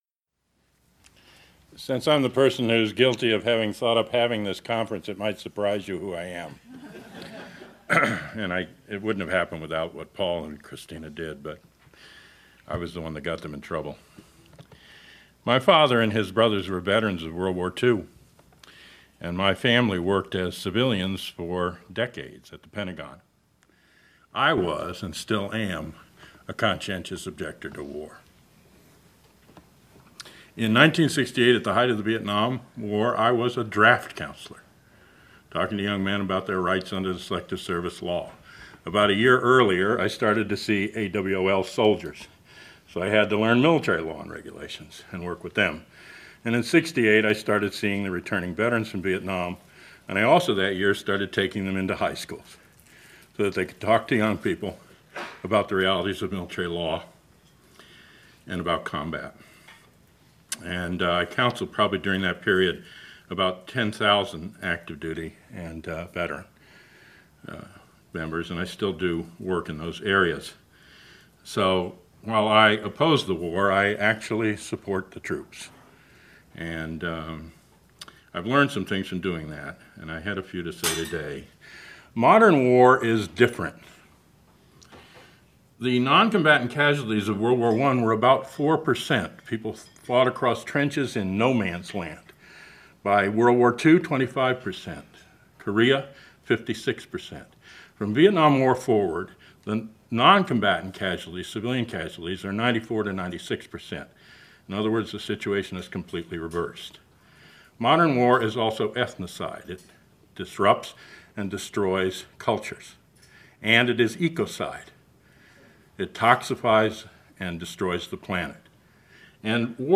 Ash Center for Democratic Governance and Innovation
Harvard University Kennedy School